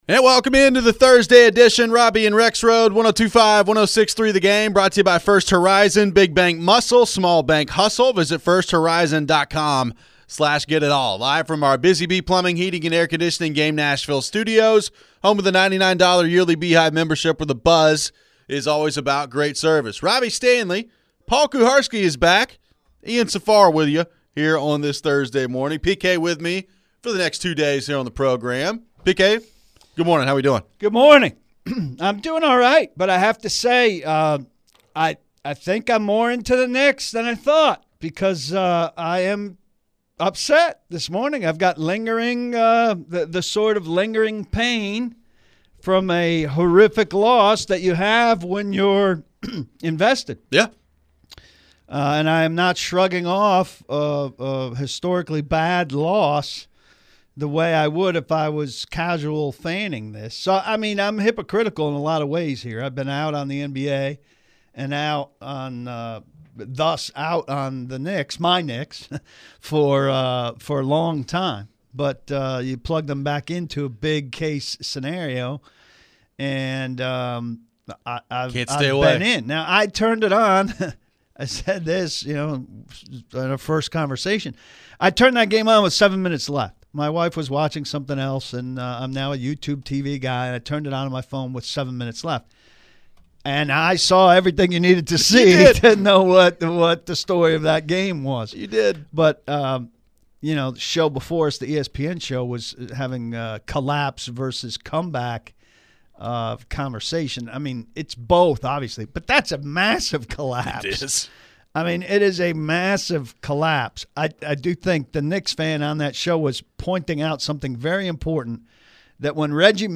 We wrap up the hour with your phones.